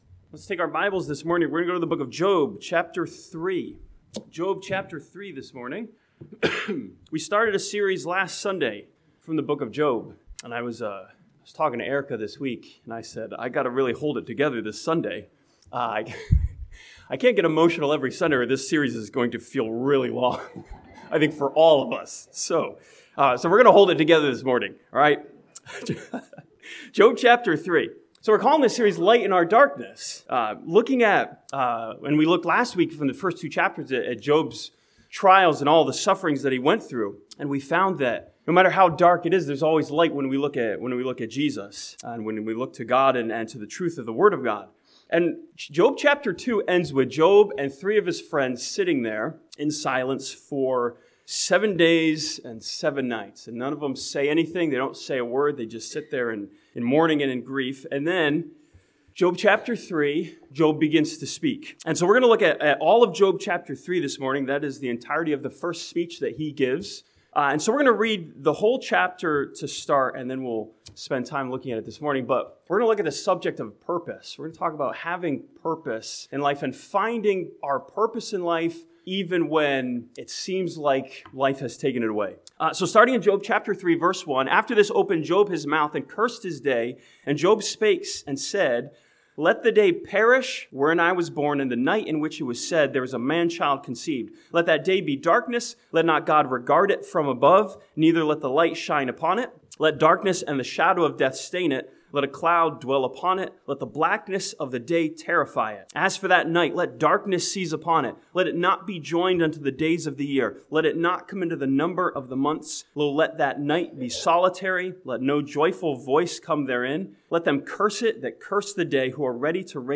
This sermon from Job chapter 3 studies Job's expression of purposelessness and finds the light of our true purpose.